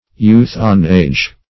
youth-on-age \youth`-on-age"\ n. (Bot.)